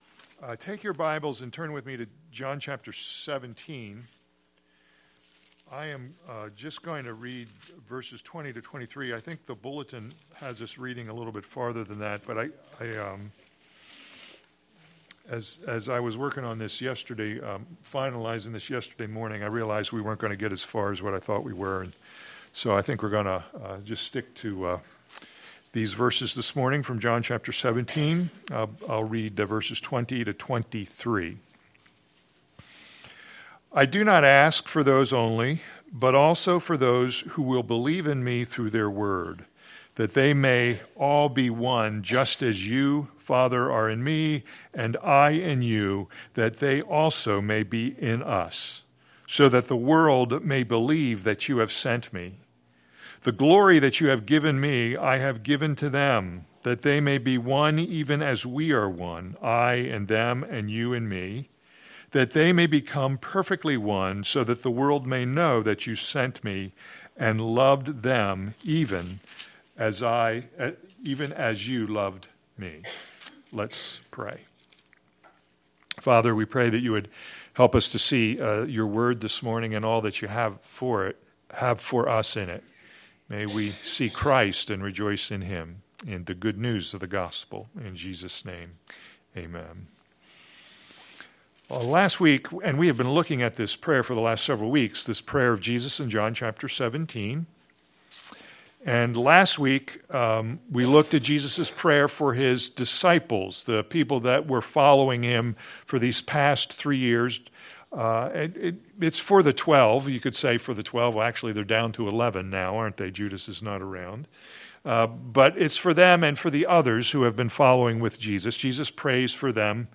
All Sermons The Lord’s Prayer for His Church